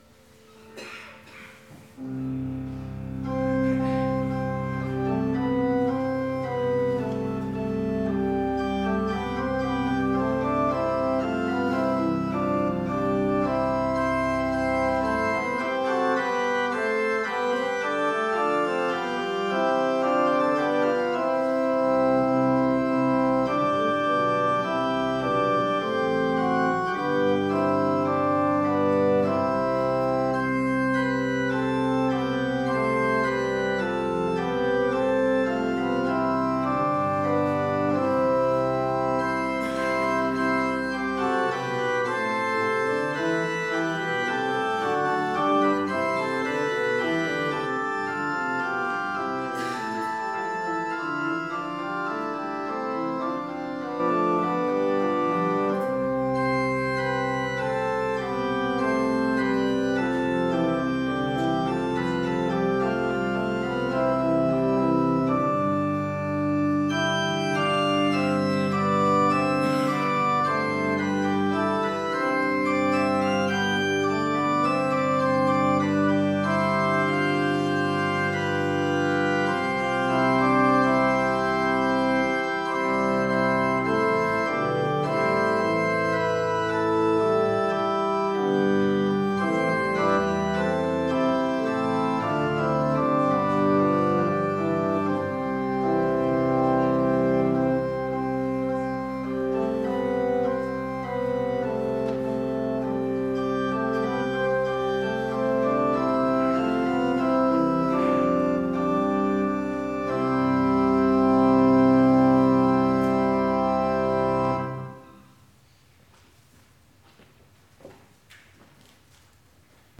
Gottesdienst am 23. November (Herzhausen)